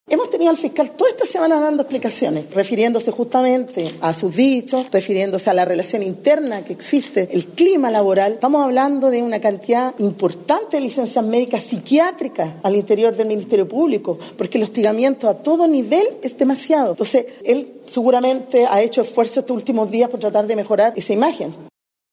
La legisladora insistió en sus críticas al máximo persecutor.